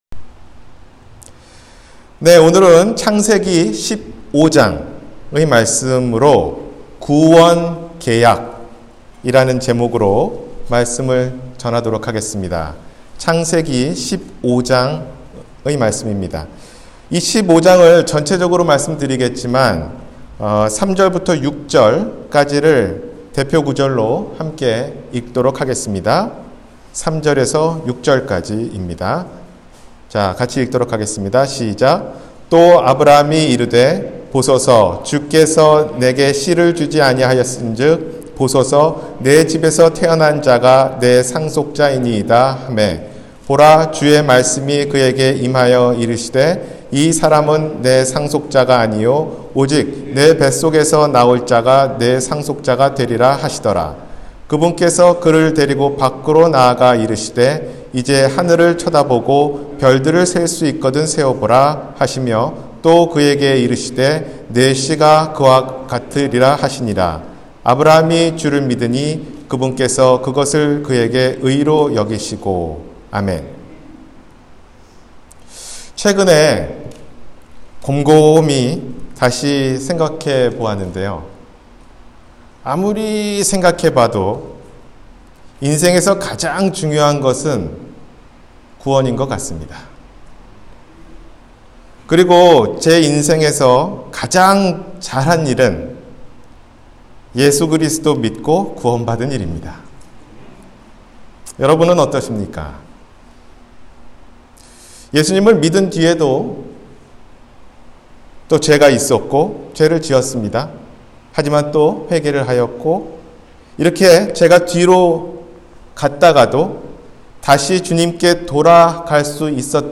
구원계약 – 주일설교